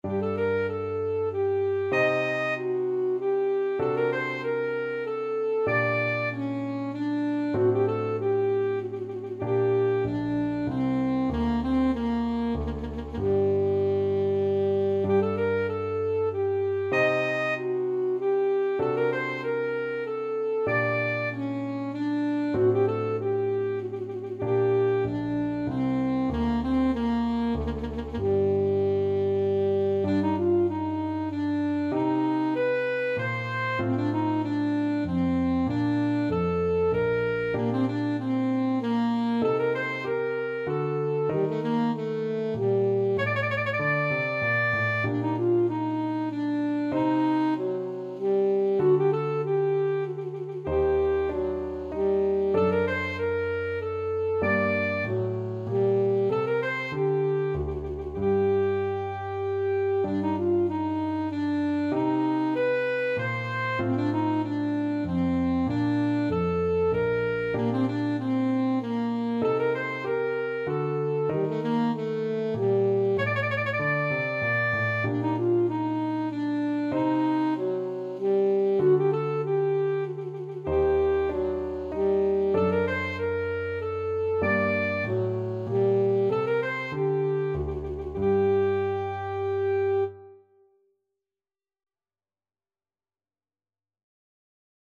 Alto Saxophone
G minor (Sounding Pitch) E minor (Alto Saxophone in Eb) (View more G minor Music for Saxophone )
3/8 (View more 3/8 Music)
Classical (View more Classical Saxophone Music)
scarlatti_k23_sonata_ASAX.mp3